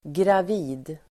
Uttal: [grav'i:d]